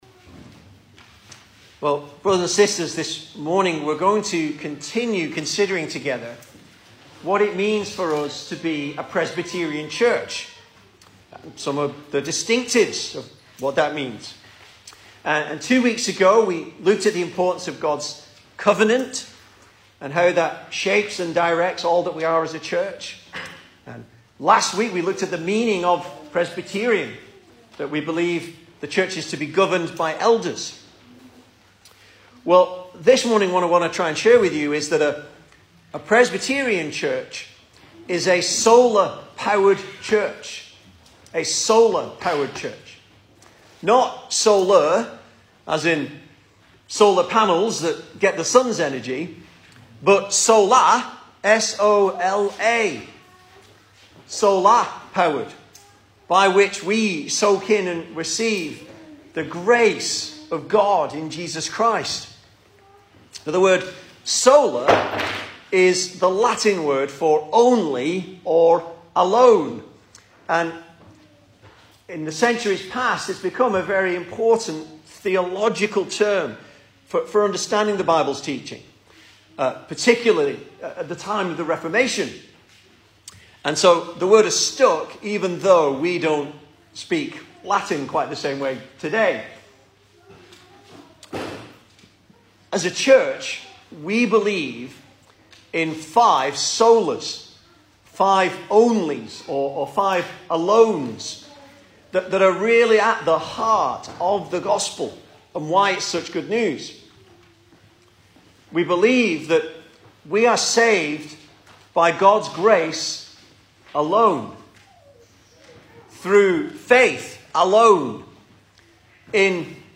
2022 Service Type: Sunday Morning Speaker